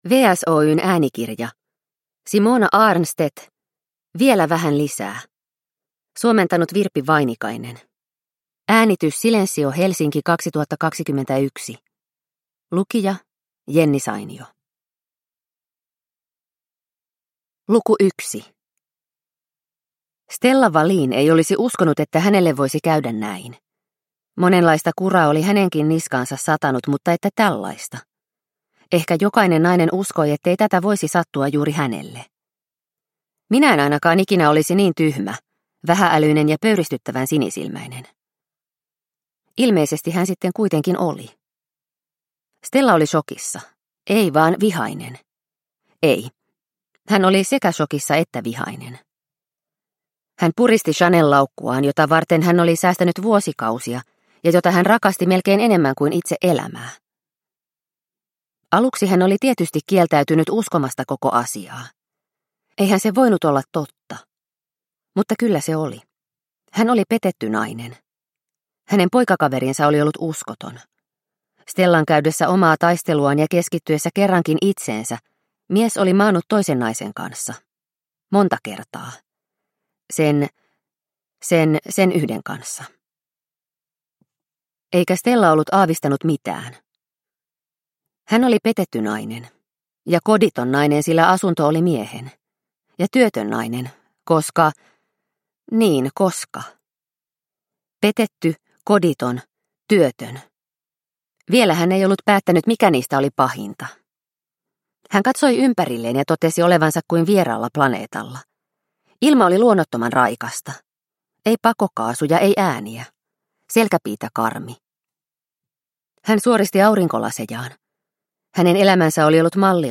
Vielä vähän lisää – Ljudbok – Laddas ner